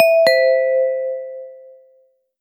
Seks ulike ringetoner
2-Ding-Dong.wav